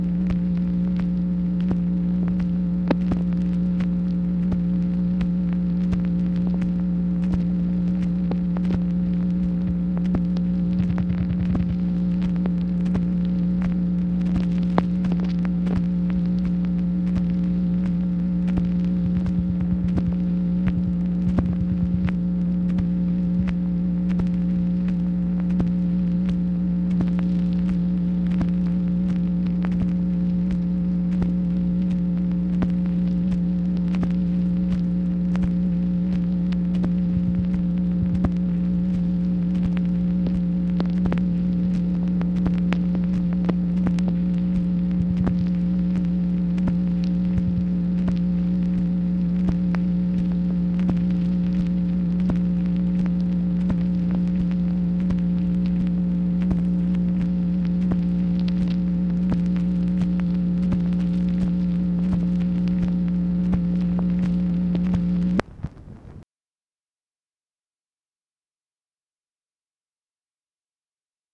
MACHINE NOISE, March 30, 1966
Secret White House Tapes | Lyndon B. Johnson Presidency